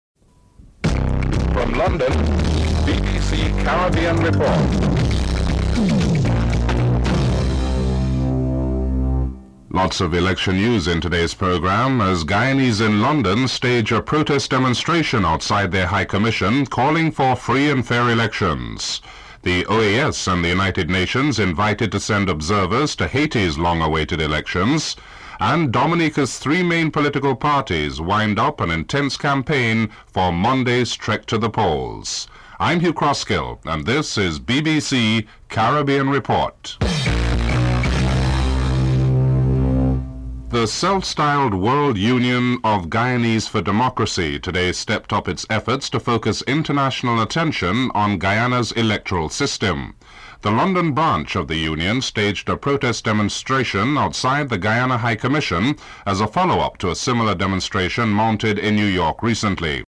Audio distorted at the beginning of the report.